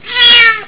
katt.au